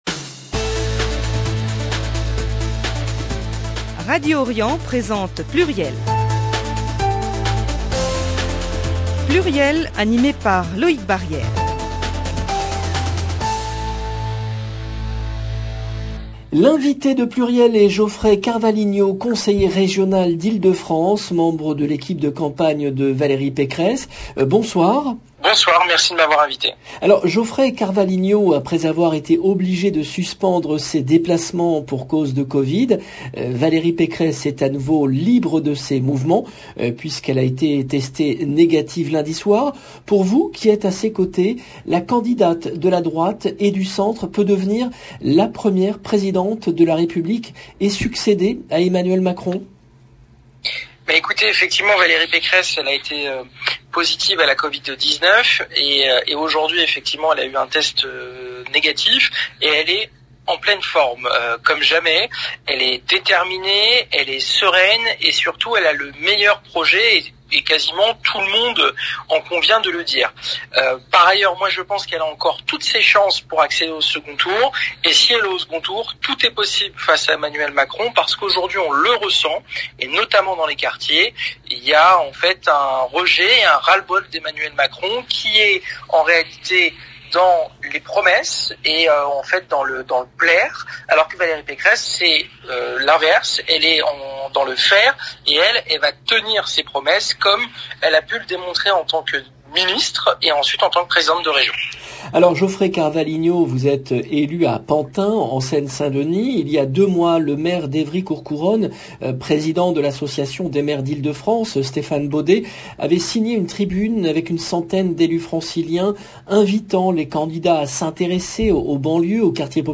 L’invité de PLURIEL est Geoffrey Carvalhinho , conseiller régional d’Ile-de-France, membre de l’équipe de campagne de Valérie Pécresse